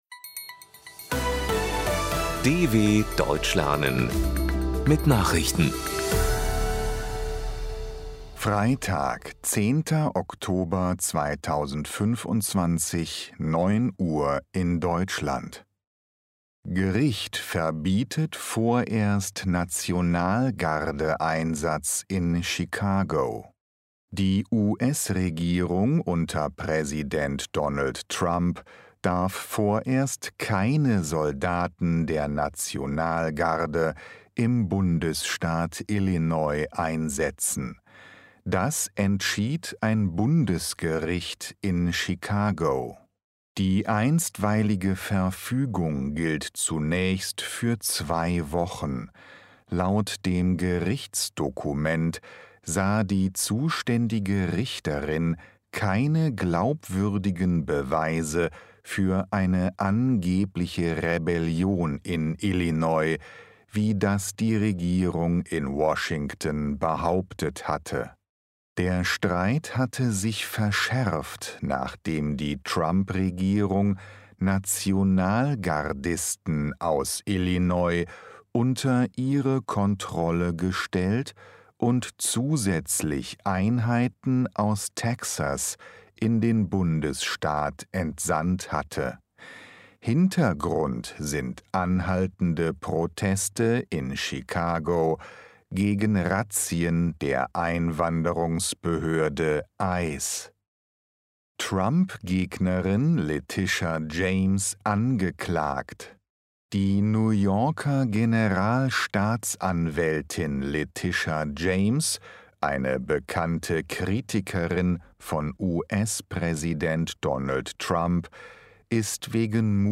10.10.2025 – Langsam Gesprochene Nachrichten
B2 | Deutsch für Fortgeschrittene: Verbessert euer Deutsch mit aktuellen Tagesnachrichten der Deutschen Welle – für Deutschlerner besonders langsam und deutlich gesprochen.
Freitag – als Text und als verständlich gesprochene Audio-Datei.